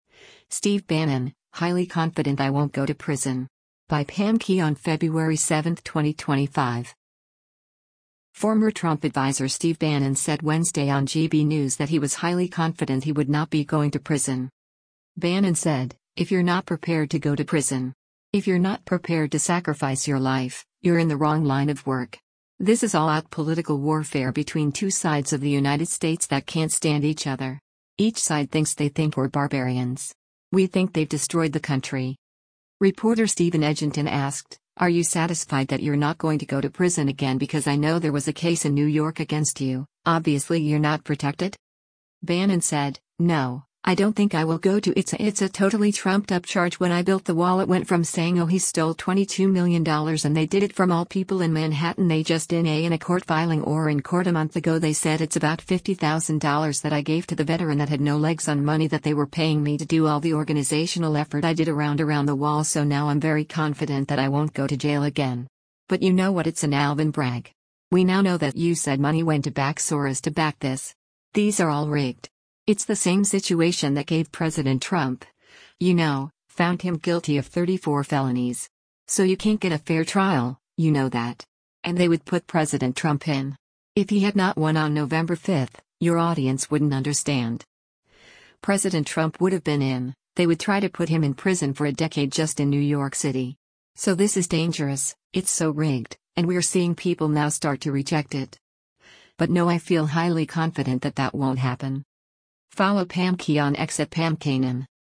Former Trump adviser Steve Bannon said Wednesday on GB News that he was “highly confident” he would not be going to prison.